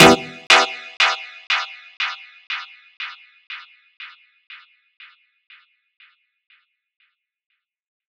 Piano Delay 1.wav